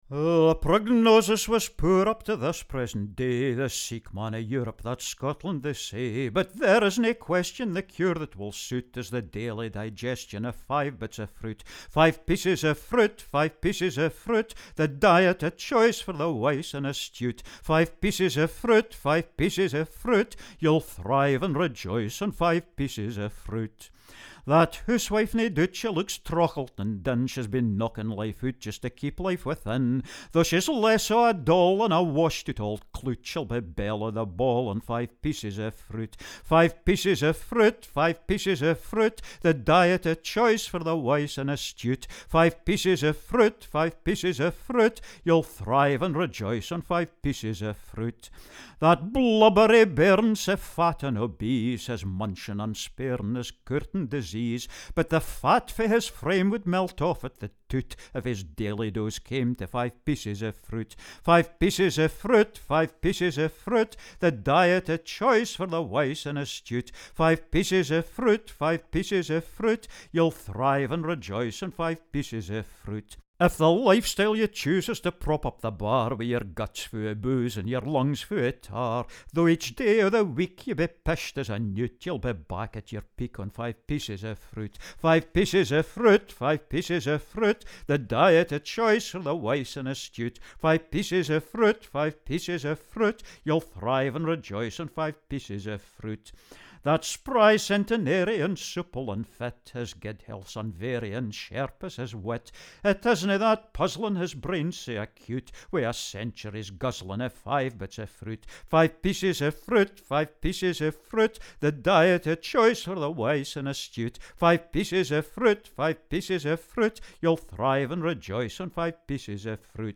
composer of Scots Songs
The tune to this is a traditional dance tune, the name of which I can't remember.